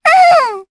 Luna-Vox_Happy1_jp_b.wav